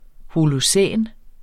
Udtale [ holoˈsεˀn ]